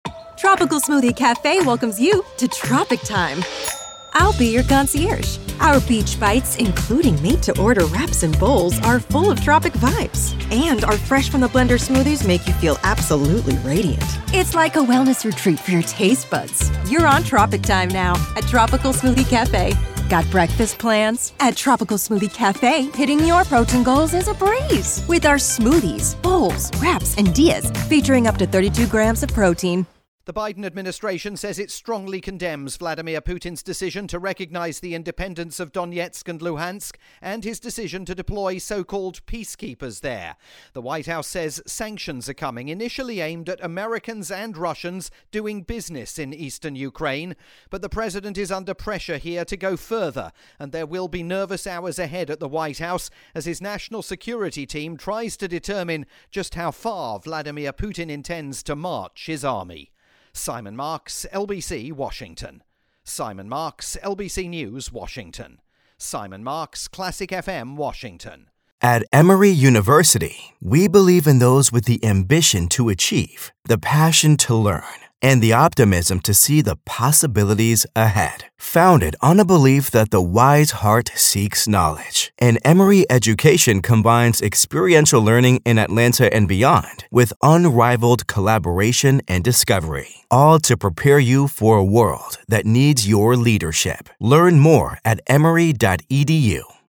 live, breaking coverage of Vladimir Putin's address to the Russian people and the US reaction to it.